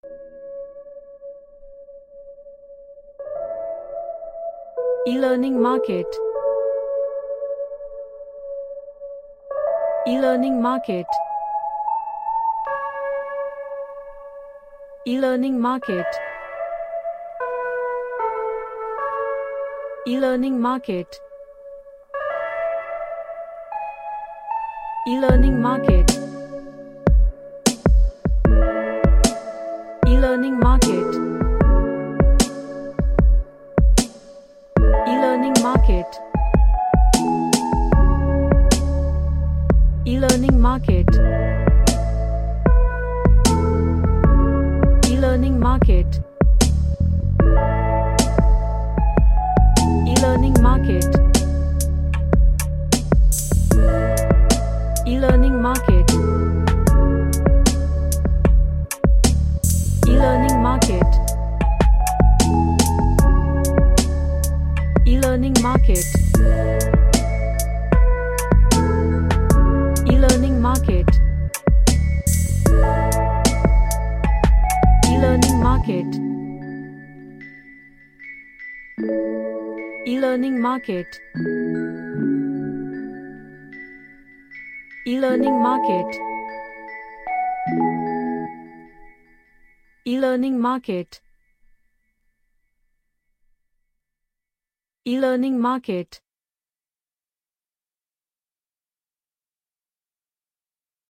A lofi chill christmas type track
Chill Out